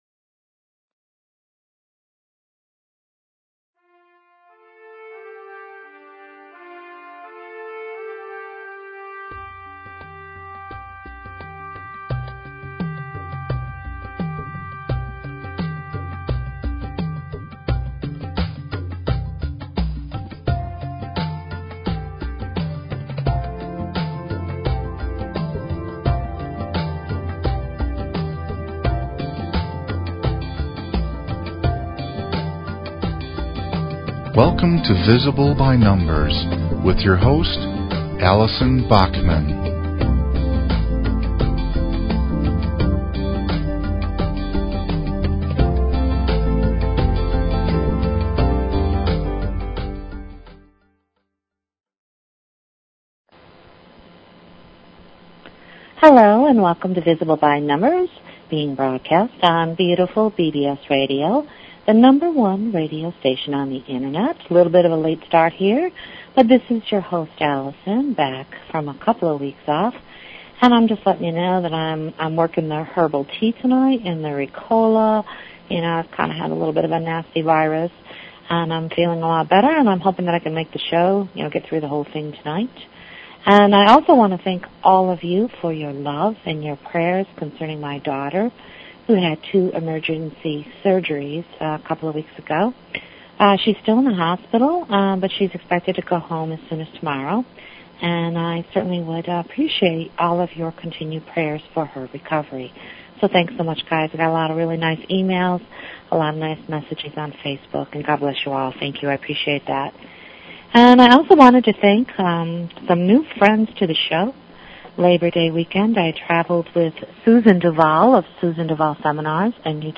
Talk Show Episode, Audio Podcast, Visible_By_Numbers and Courtesy of BBS Radio on , show guests , about , categorized as